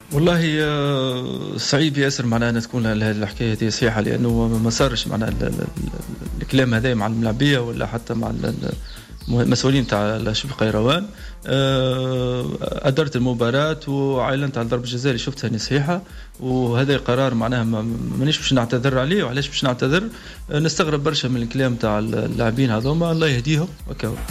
في تصريح خصّ به راديو جوهرة أف أم